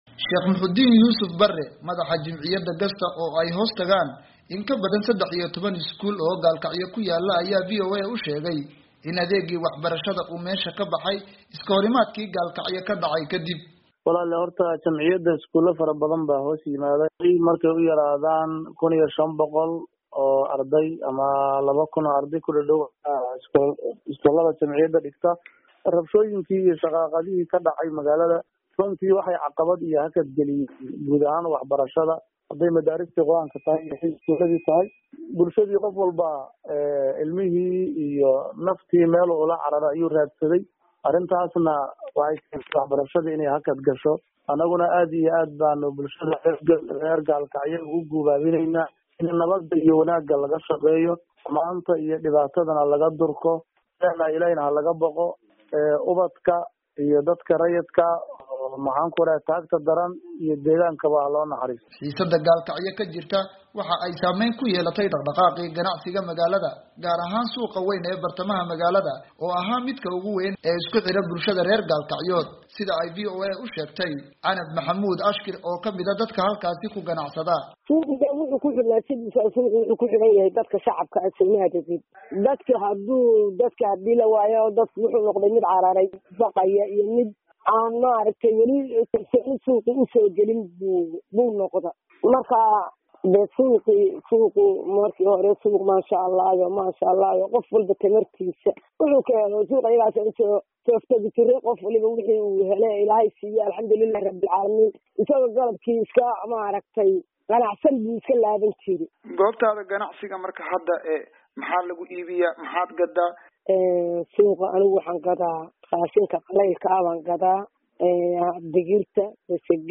Warbixin: Xaaladda Gaalkacyo